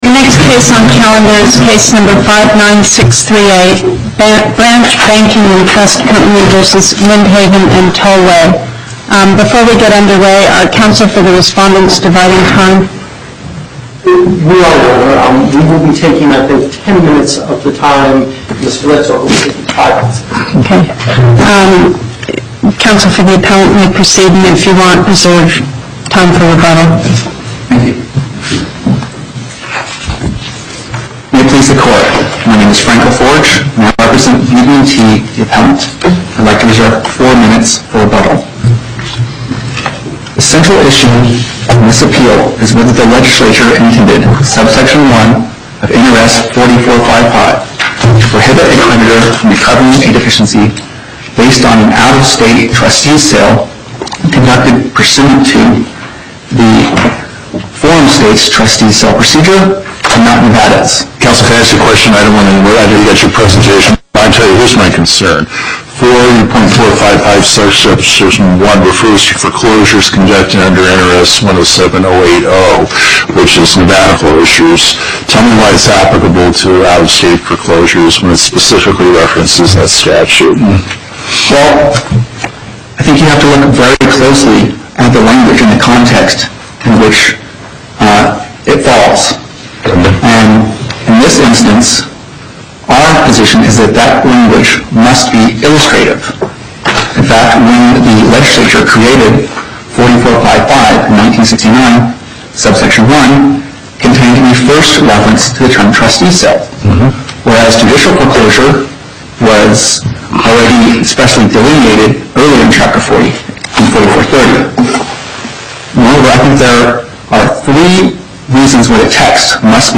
Location: Las Vegas Before the En Banc Court, Chief Justice Pickering Presiding